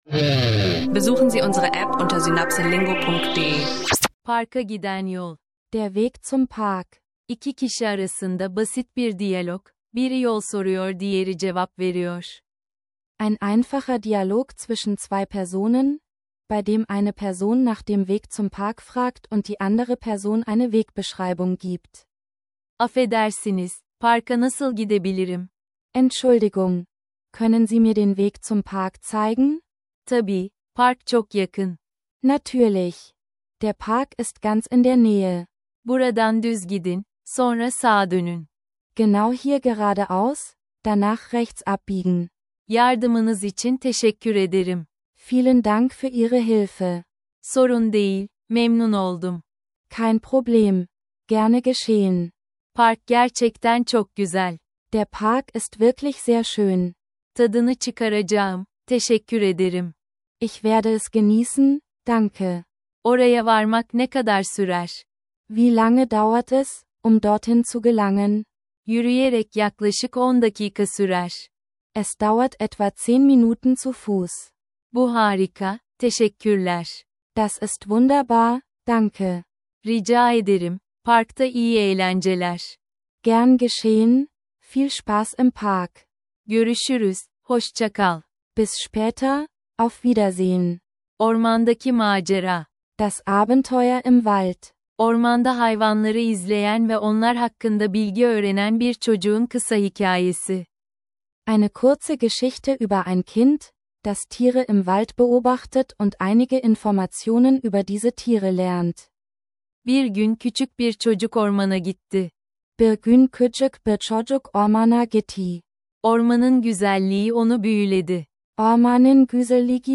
Begleite uns in einem einfachen Gespräch auf Türkisch und lerne, wie man nach dem Weg fragt. Perfekt für Anfänger und alle, die Türkisch im Alltag nutzen möchten.